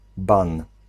Ääntäminen
Ääntäminen Tuntematon aksentti: IPA: /ban̪/ Haettu sana löytyi näillä lähdekielillä: puola Käännös Substantiivit 1. ban Suku: m .